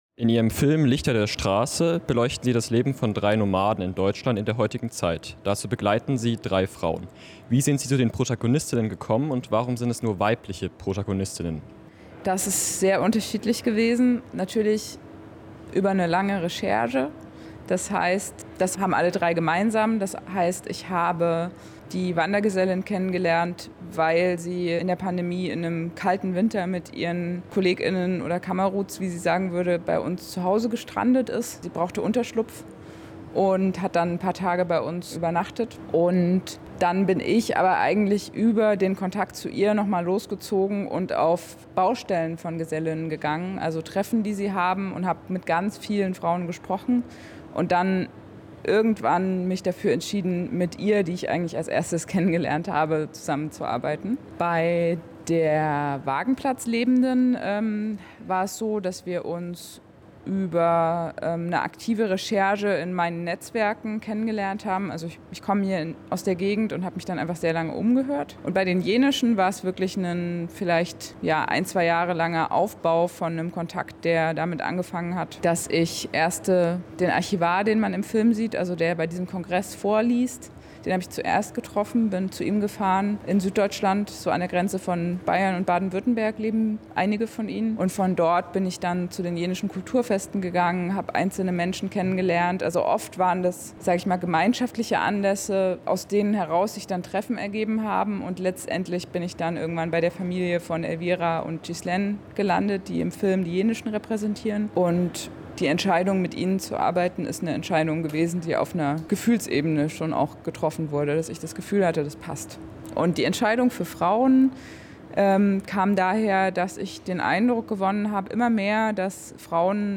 Von DOK Spotters 2024Audio, Interview
Interview_Lichter-der-Strasse_final.mp3